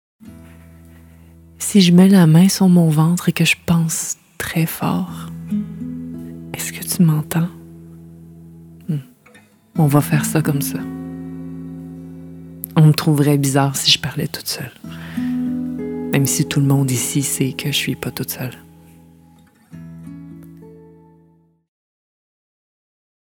Timbre Grave - Médium
Petit Lion - Vulnérable - Douce - Québécois naturel /
Narration - Court-métrage 2023 0:23 913 Ko